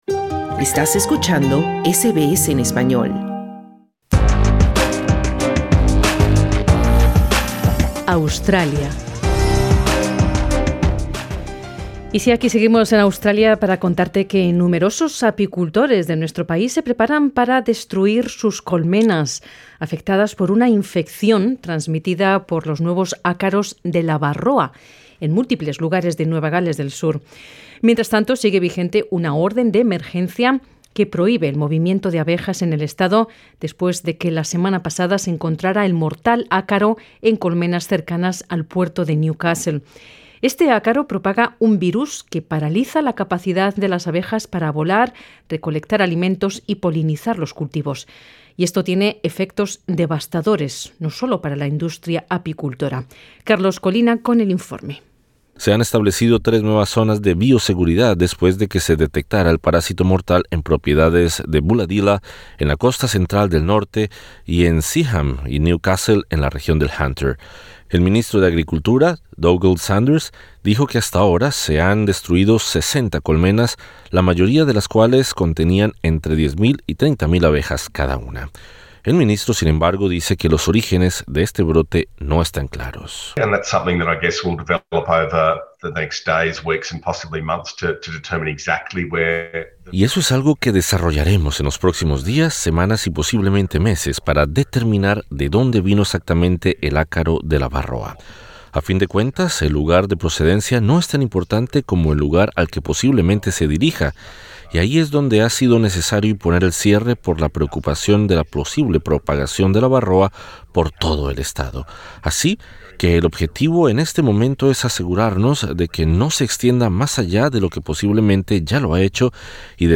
En SBS Spanish conversamos con una apicultora de nuestra comunidad afectada por este brote.